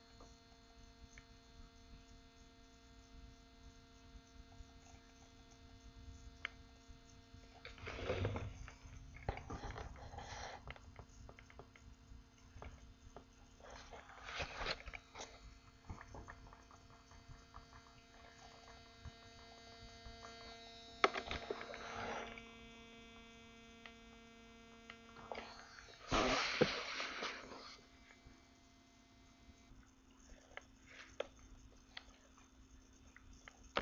Boom buzzing recording
I don't have a good microphone at home, but this sounds like probably a square wave to me or a "pulse train" at a frequency of no more than 200 hz. It is interesting that it sounds like my recording has a different fundamental frequency than the previous recording, but not by much. The sound gets louder and softer with the volume buttons, and is not affected by changing the line-in level.